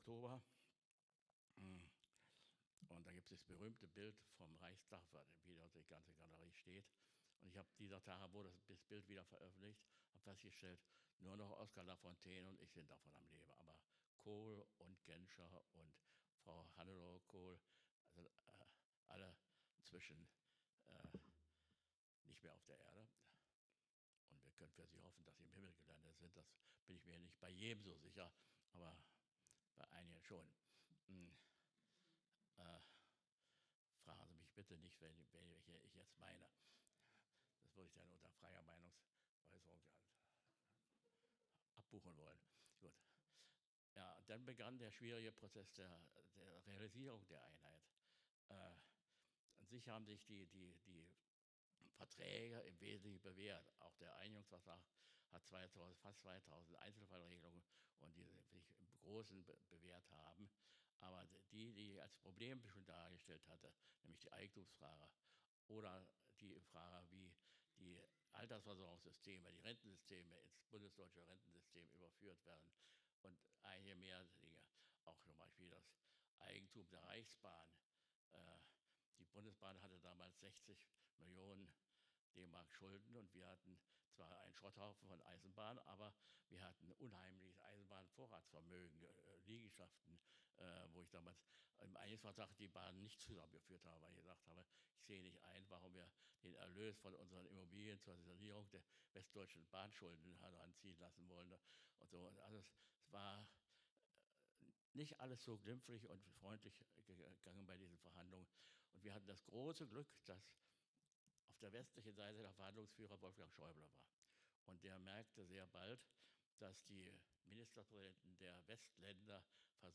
Rede von Lothar de Maizière Teil III